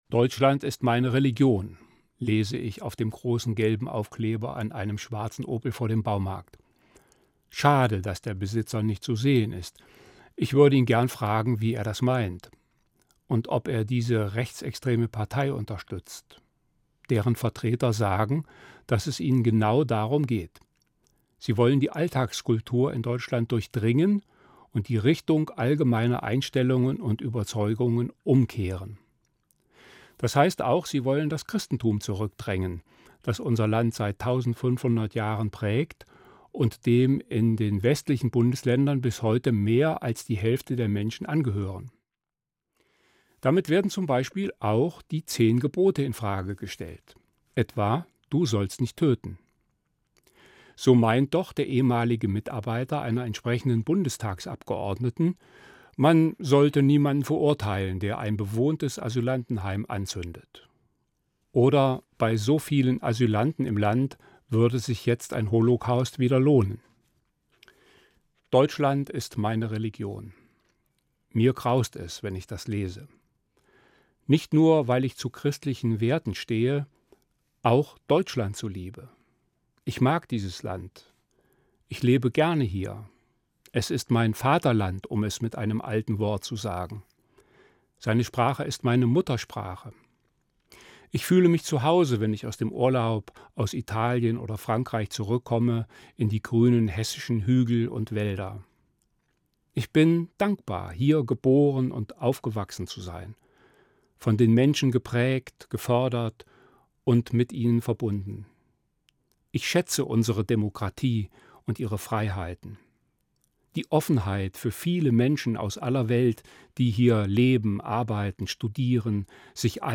Evangelischer Pfarrer, Marburg